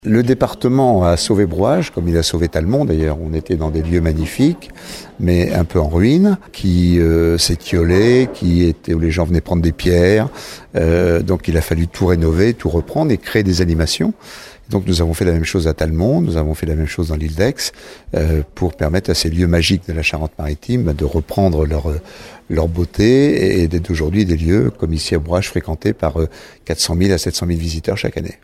Dominique Bussereau, le président de la Charente-Maritime :